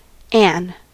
Ääntäminen
Vaihtoehtoiset kirjoitusmuodot Ann Ääntäminen US RP : IPA : /ˈæn/ US : IPA : /ˈɛən/ Haettu sana löytyi näillä lähdekielillä: englanti Käännöksiä ei löytynyt valitulle kohdekielelle.